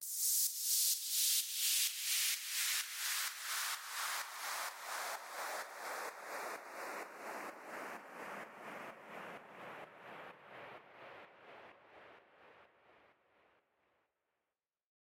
三弦回声低音提琴
描述：用电吉他演奏的4个无缝小节的三连音洗牌，有回声效果。
Tag: 112 bpm Rock Loops Bass Guitar Loops 1.45 MB wav Key : F